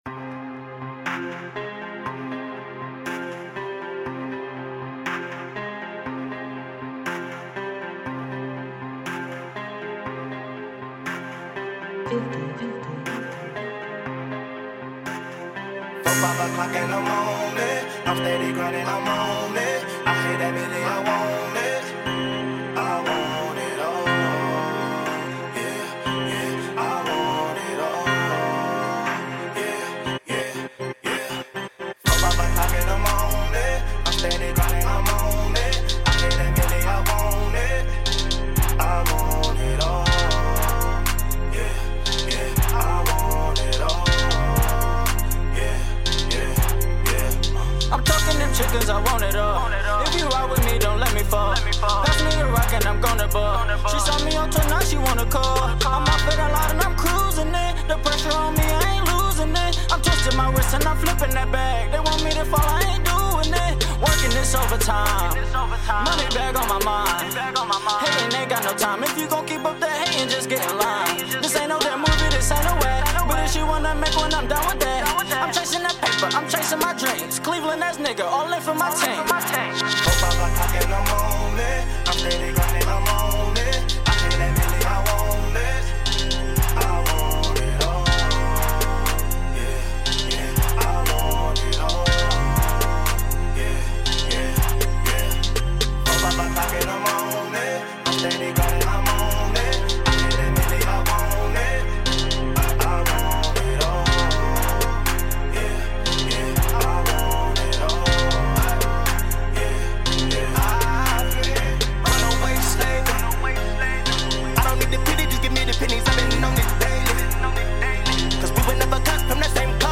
Hiphop
really showcasing the new Cleveland sound!!